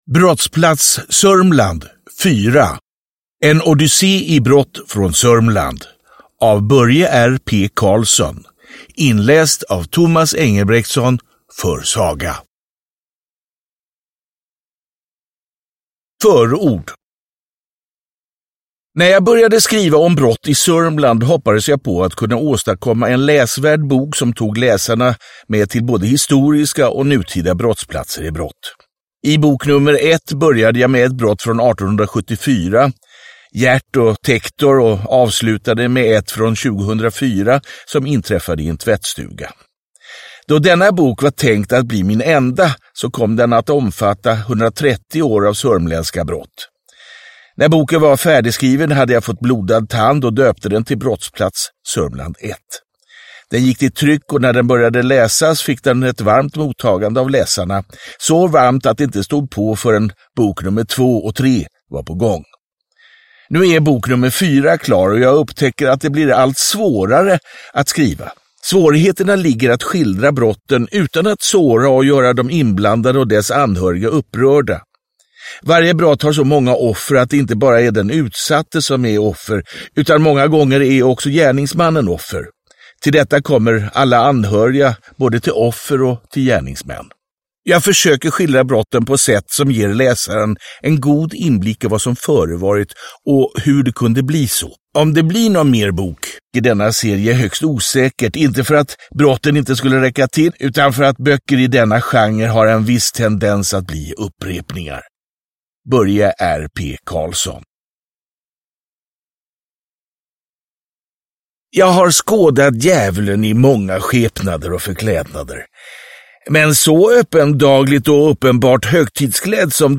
Brottsplats Sörmland. 4, En odyssé av brott – Ljudbok – Laddas ner